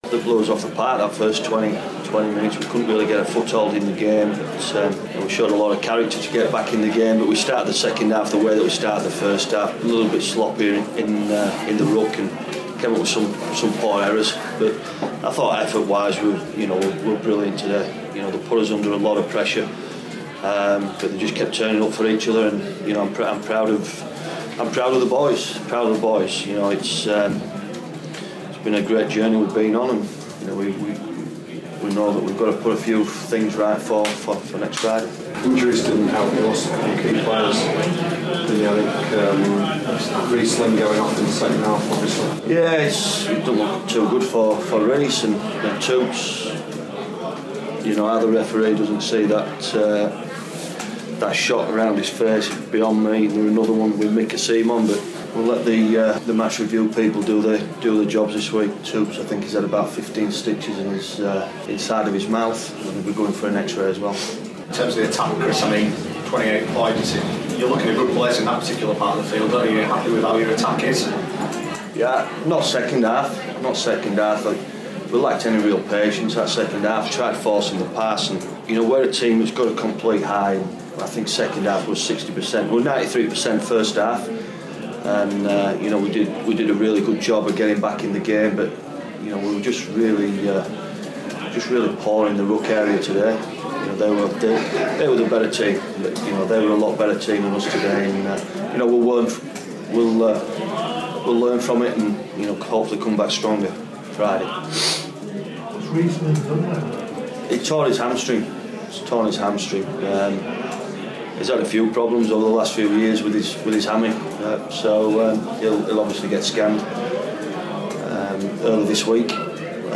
speaks to the press after their loss against Hull FC at the Belle Vue